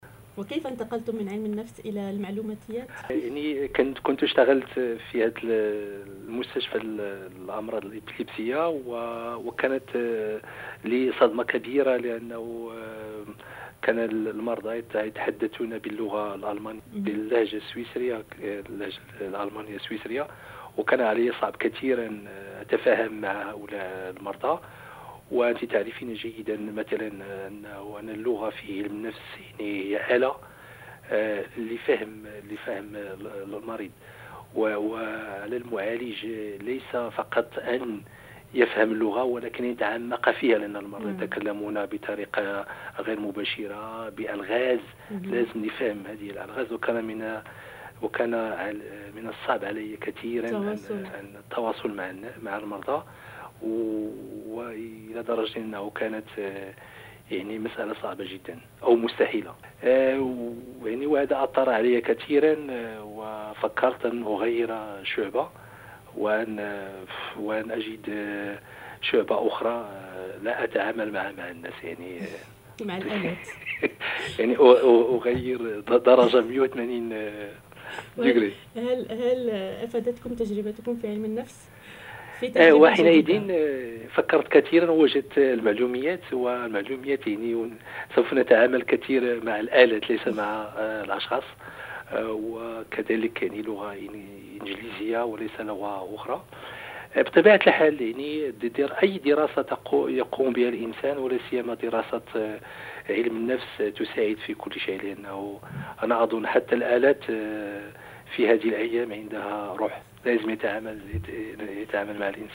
الباحث المغربي